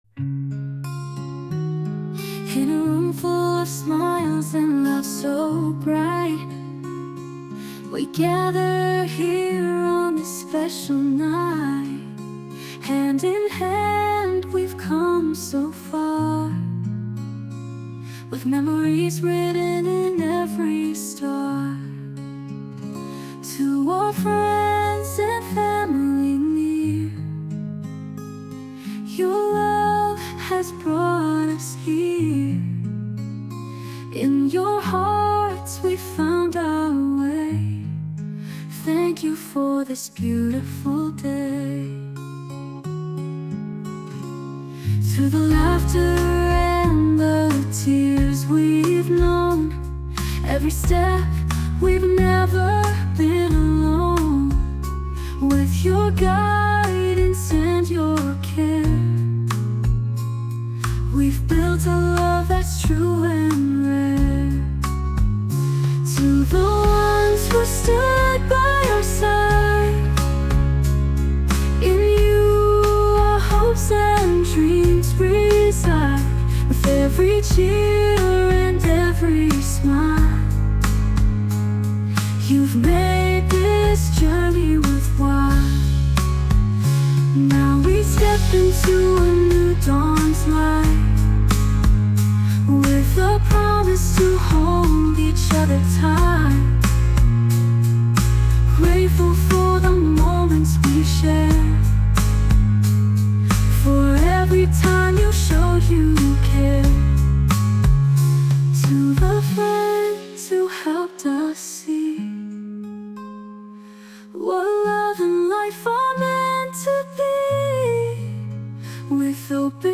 洋楽女性ボーカル著作権フリーBGM ボーカル
著作権フリーのオリジナルBGMです。
女性ボーカル曲（洋楽・英語）です。